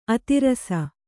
♪ ati rasa